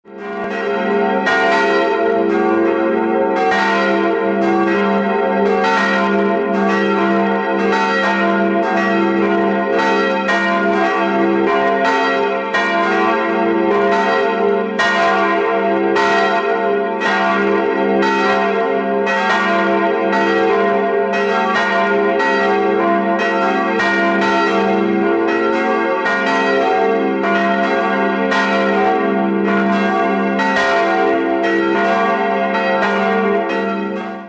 Großes Geläut St. Petri Großburgwedel
St.-Petri-Glockengeläut Großes Geläut St. Petri Großburgwedel